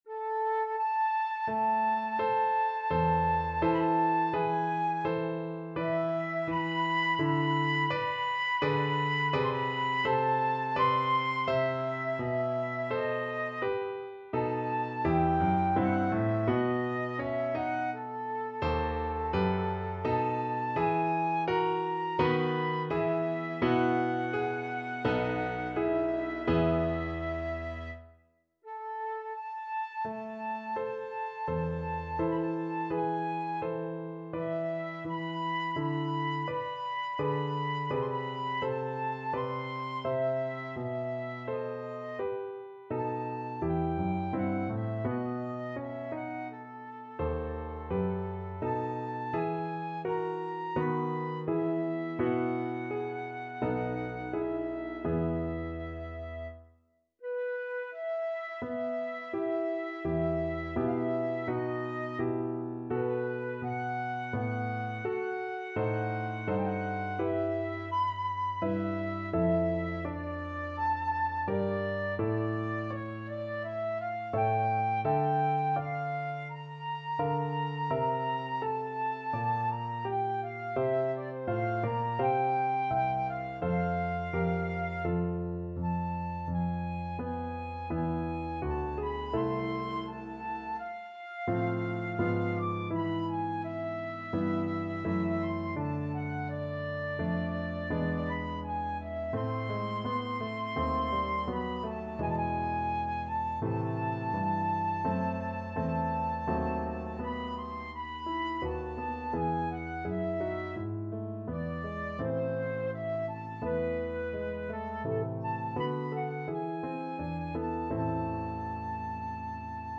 4/4 (View more 4/4 Music)
Largo =42
Flute  (View more Intermediate Flute Music)
Classical (View more Classical Flute Music)